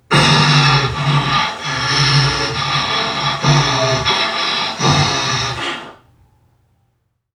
NPC_Creatures_Vocalisations_Robothead [67].wav